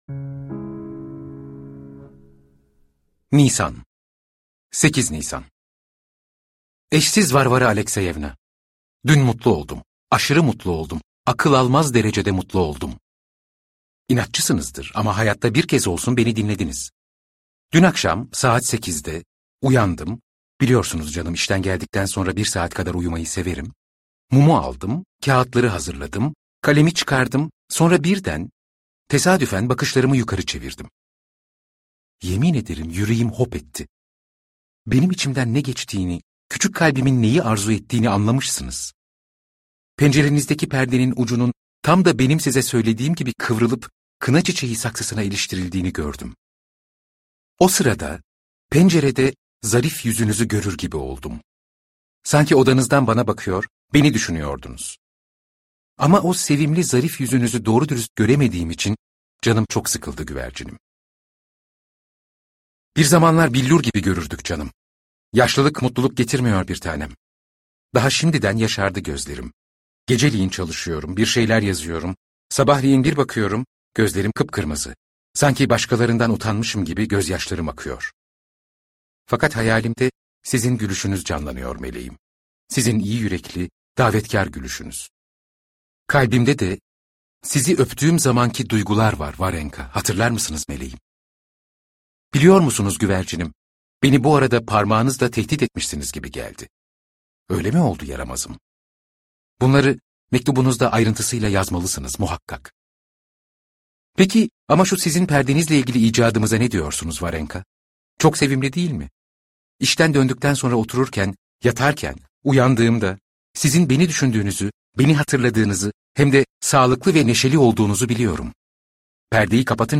İnsancıklar - Seslenen Kitap